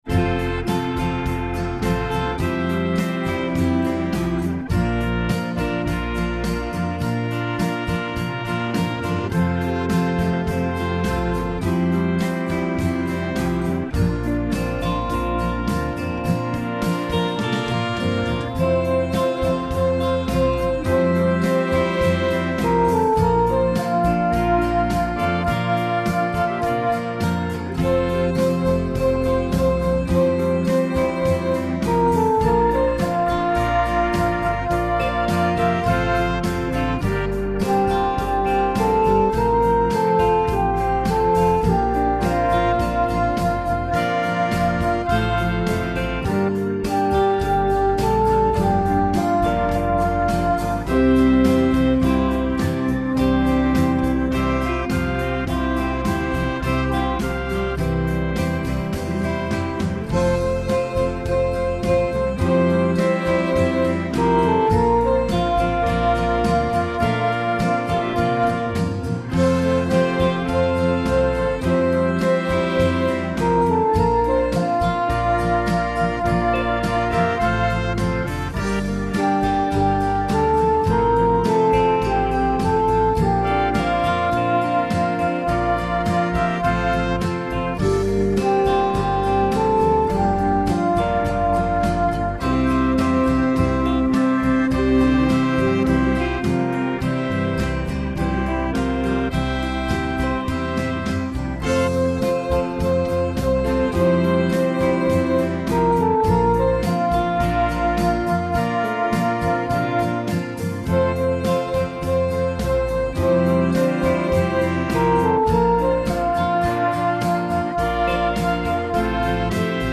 It is a beautiful meditative piece.